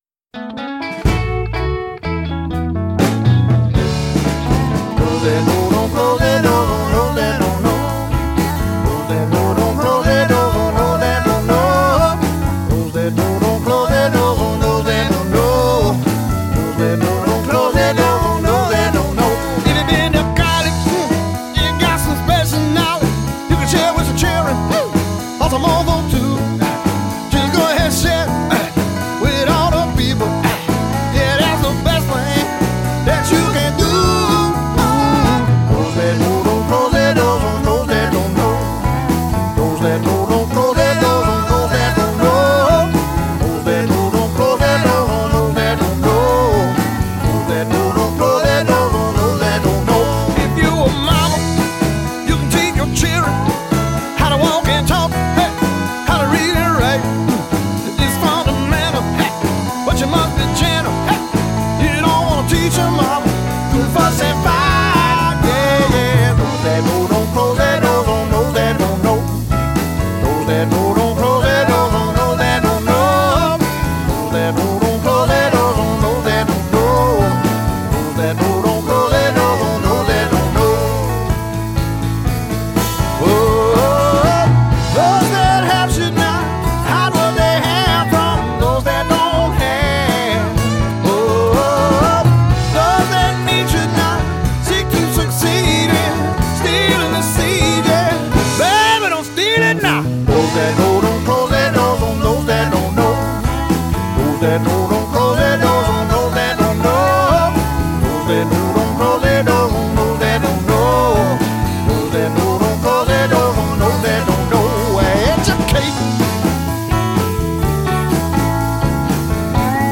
Some casually rendered yet solid wisdom